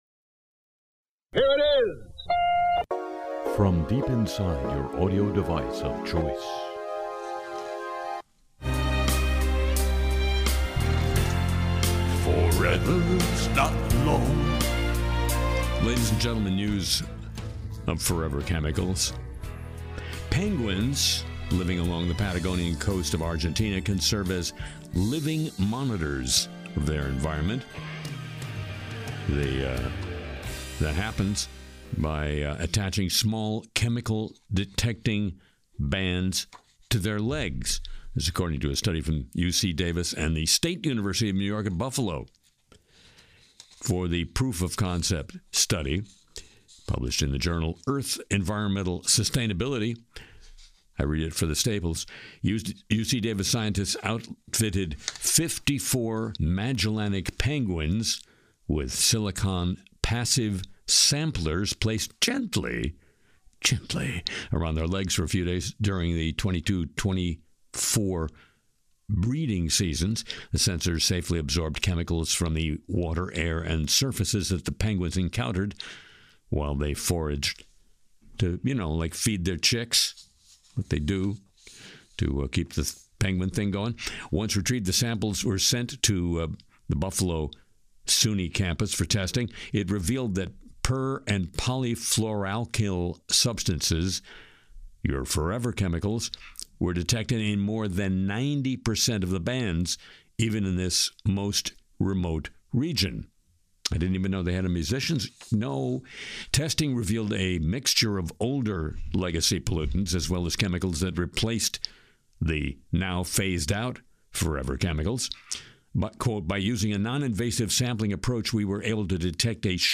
Harry Shearer’s Le Show (Apr 12 2026) spoofs Trump and covers AI fakes, chatbot influence, and failing projects.